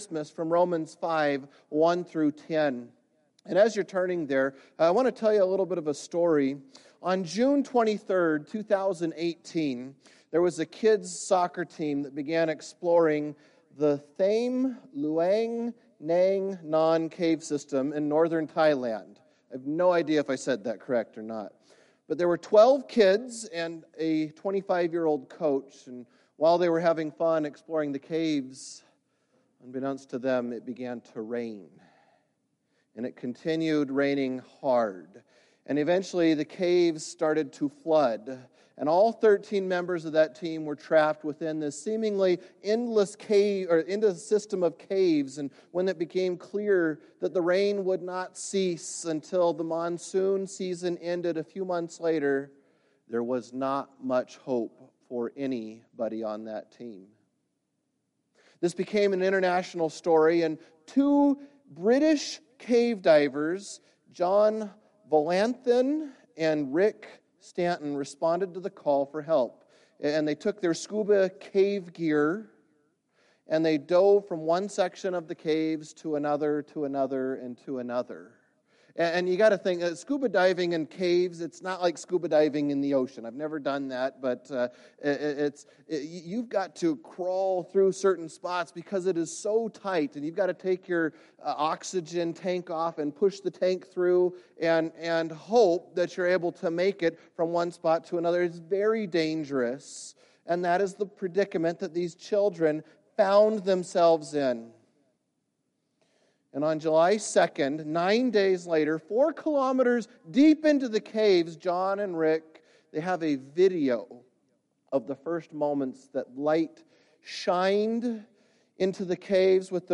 "Christmas Cantata